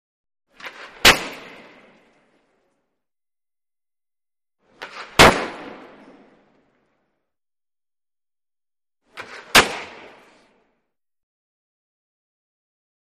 Puck Hits Glass | Sneak On The Lot
Hockey: Puck Shot To Glass ( 3x ); Hockey Puck Aggressively Impacting Plexiglas Wall; Three Times, Clank / Rattle, Close Perspective On Glass.